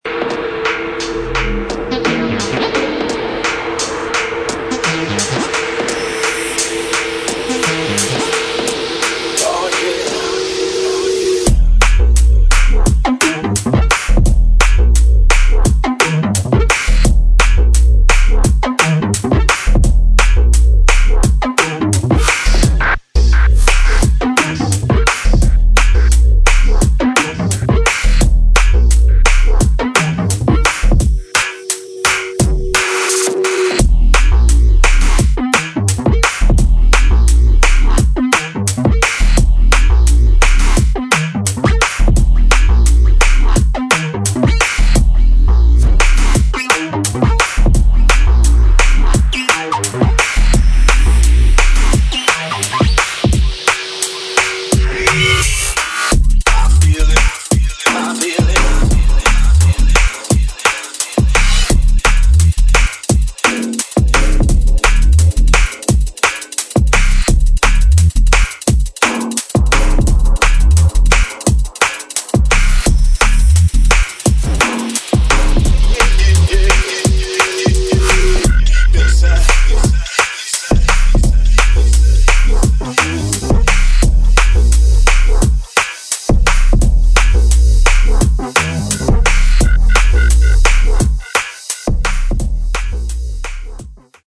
[ DRUM'N'BASS / JUNGLE / BASS ]